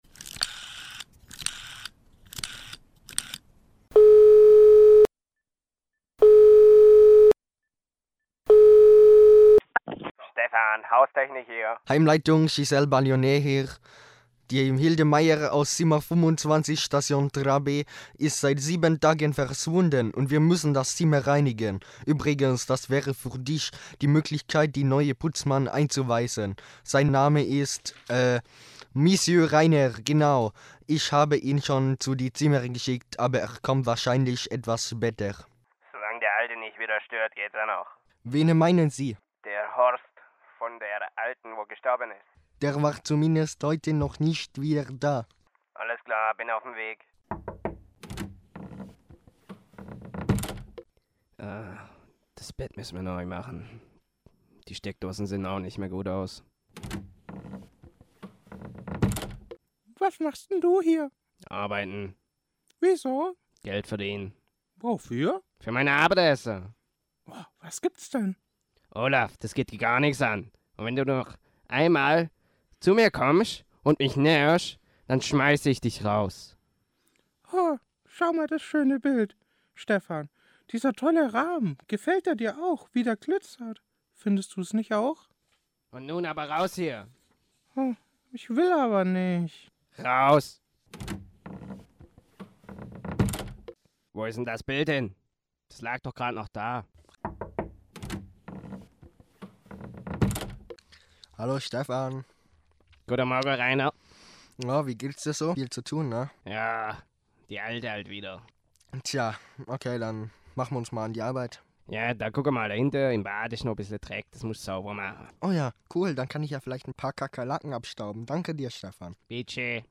Hörspiel FSJ 2017:„Die Wilde Hilde“
fand hier im Radio WW Tübingen vom 20.02 bis zum 22.02 ein Hörspiel-Workshop statt
In diesem Workshop produzierten acht junge Freiwillige unter professioneller Anleitung in zwei Gruppen zwei Hörspiele.